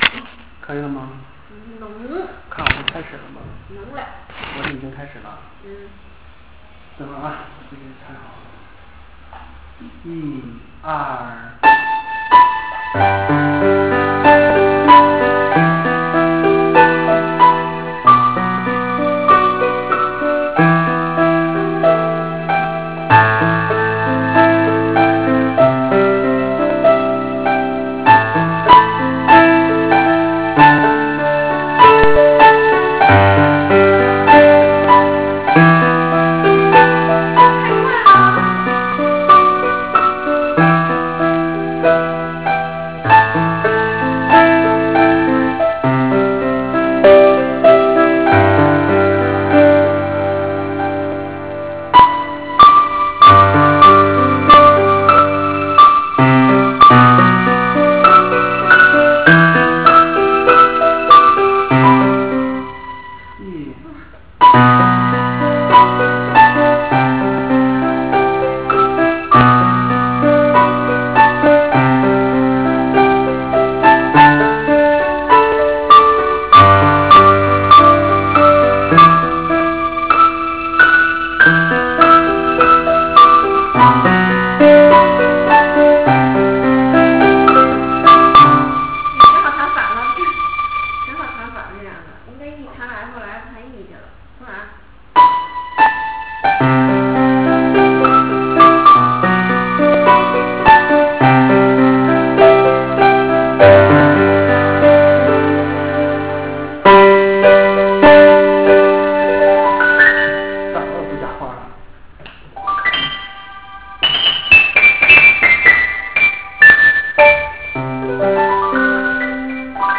0002-钢琴名曲朋友.wav